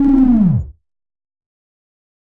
描述：与"Attack Zound04"相似，但衰减时间较长，最后有一些奇怪的东西。这个声音是用Cubase SX中的Waldorf Attack VSTi制作的。
Tag: 电子 SoundEffect中